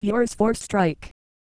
Worms speechbanks
dragonpunch.wav